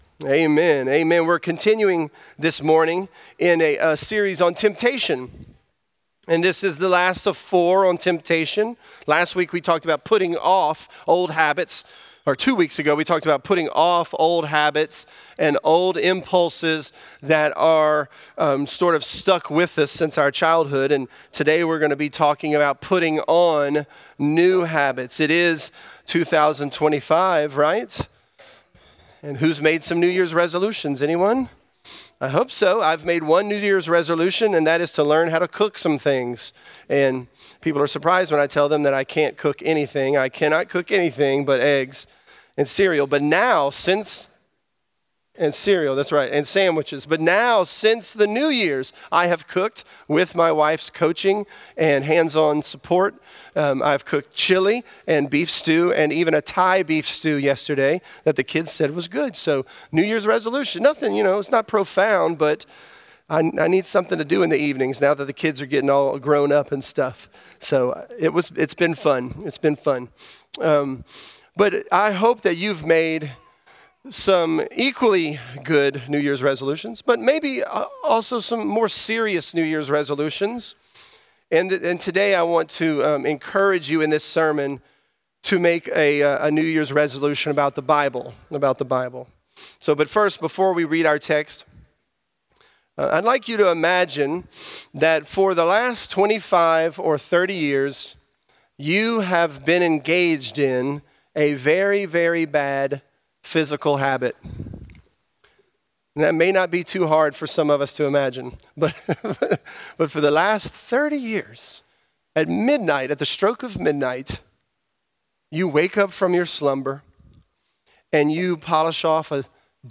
Temptation: New Year; New Habits | Lafayette - Sermon (James 1)